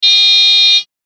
機械・乗り物 （94件）
クラクション単音単発.mp3